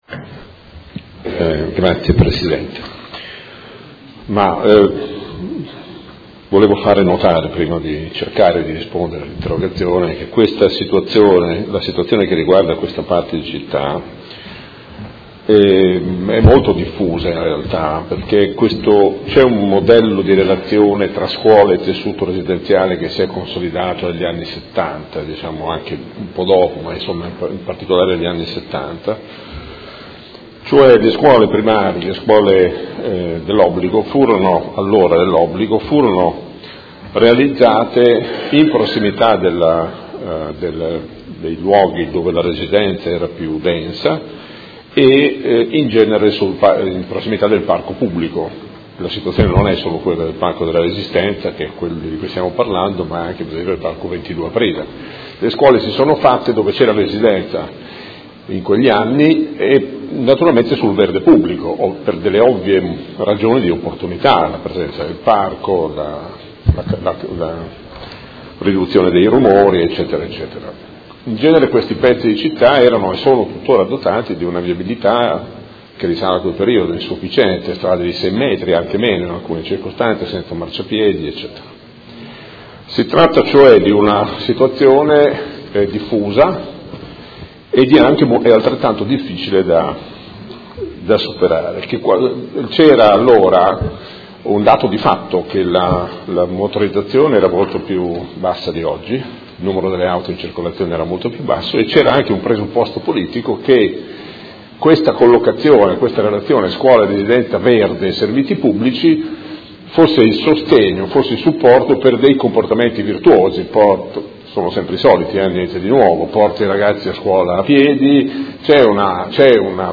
Seduta del 21/12/2017. Risponde a interrogazione del Consigliere Rocco (Art.1-MDP/Per Me Modena) avente per oggetto: Disagio viabilità Via La Spezia/Via Oneglia